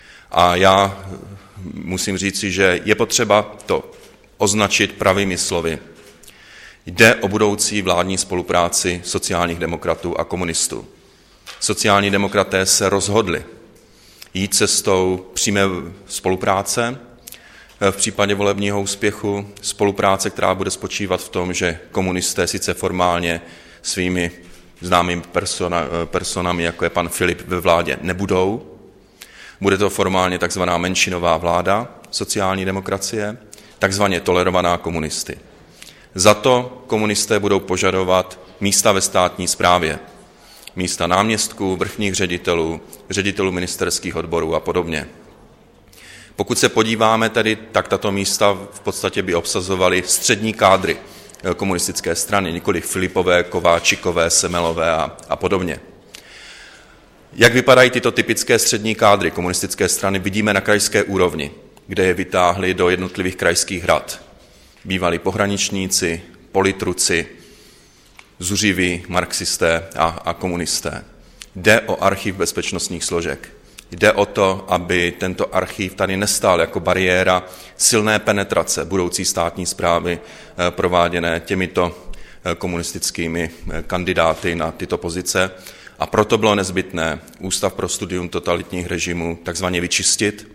Komentář předsedy vlády Petra Nečase k situaci v Ústavu pro studium totalitních režimů po setkání s bývalým ředitelem Danielem Hermanem.